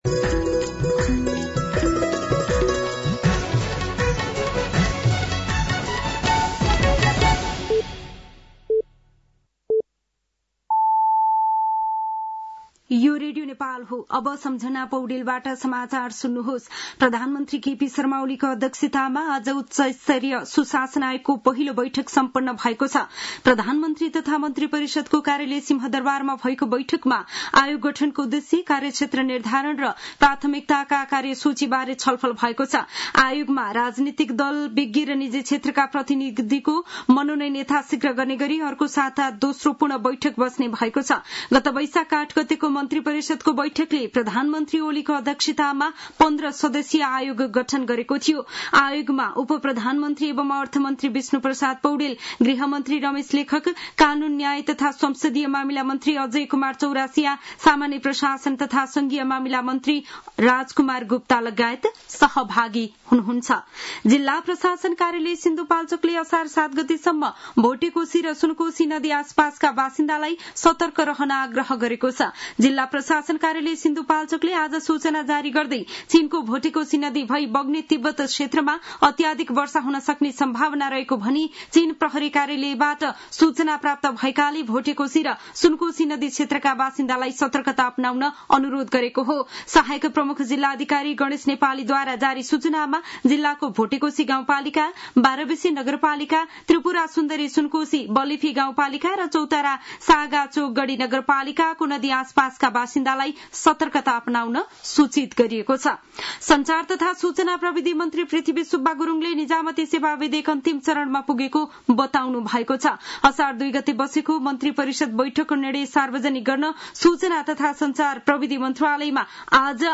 साँझ ५ बजेको नेपाली समाचार : ४ असार , २०८२